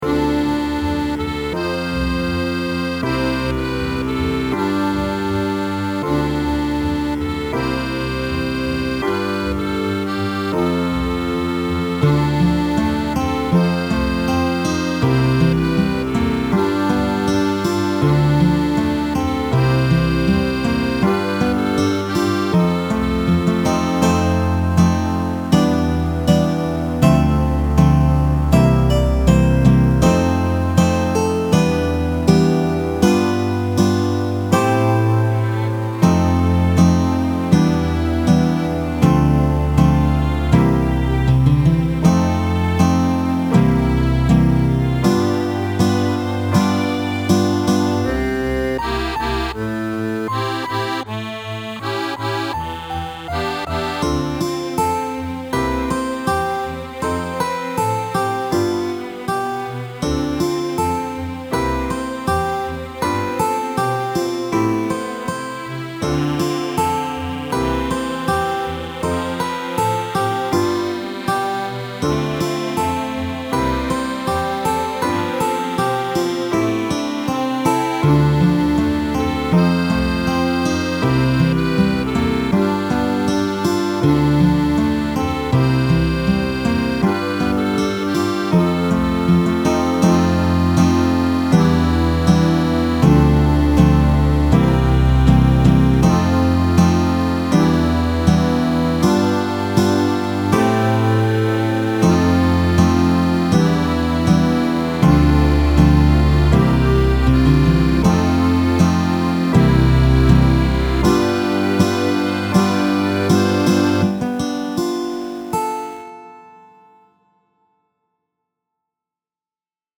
BGM
カントリー暗い